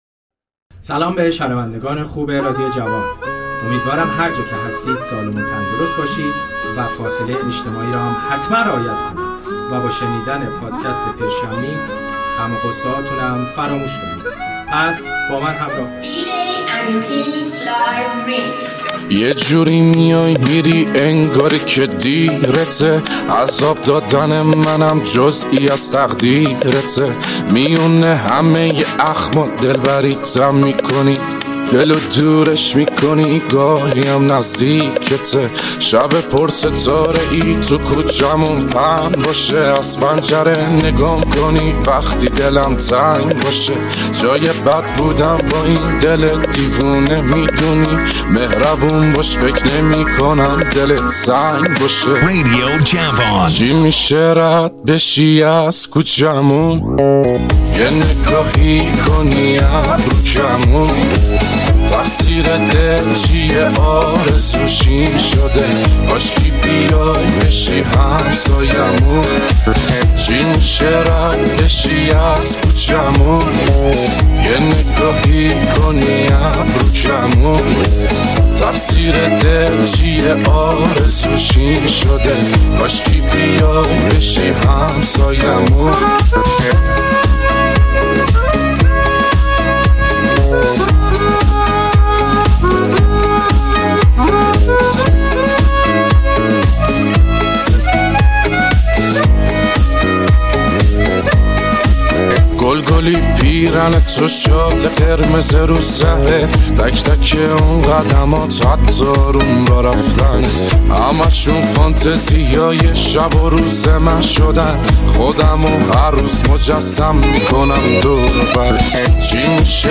میکس و زیبا از آهنگهای ایرانی
ریمیکس طولانی ایرانی